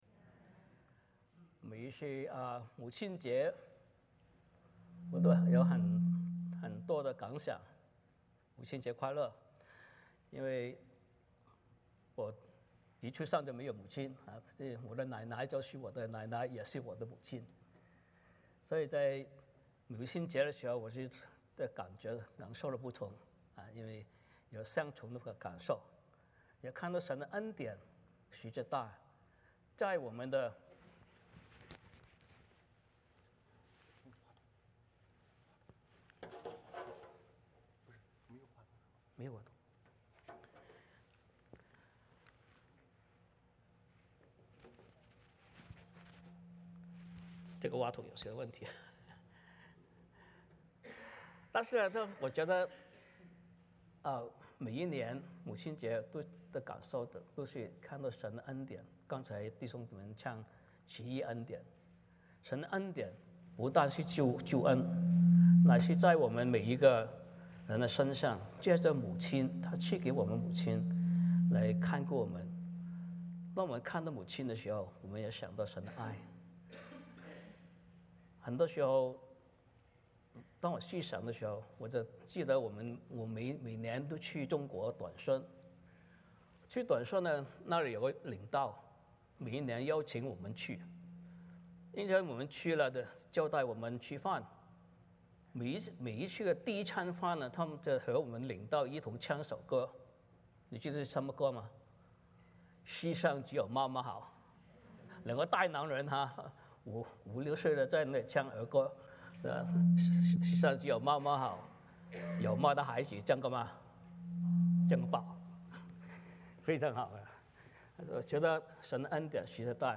使徒行传 2:1-21 Service Type: 主日崇拜 欢迎大家加入我们的敬拜。